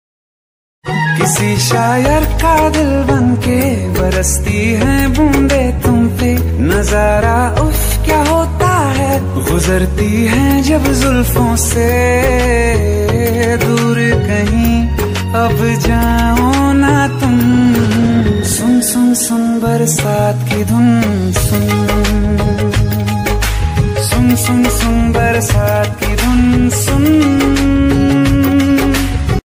Categories Baarish Ringtones / Rain Ringtones